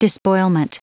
Prononciation du mot despoilment en anglais (fichier audio)